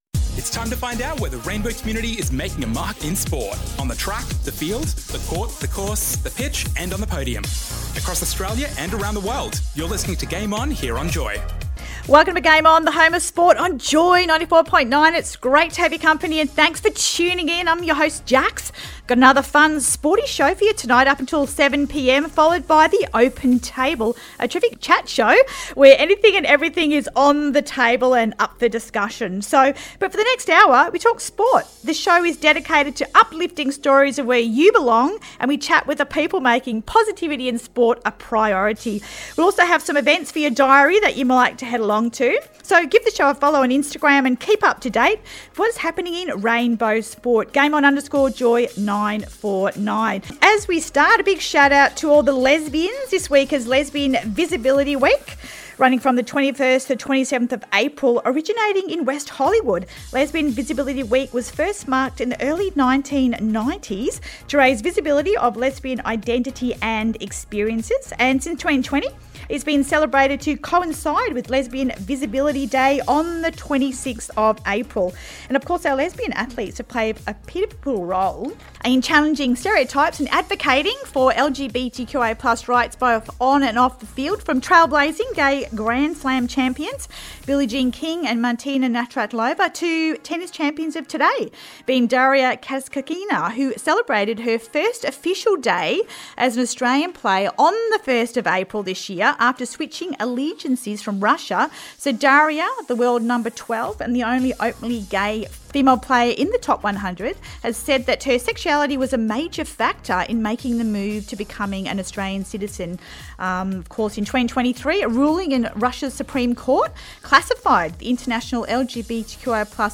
Welcome to Game On! JOY 94.9’s Home of Sport every Tuesday from 7-8pm where you’ll hear where you can get your game on!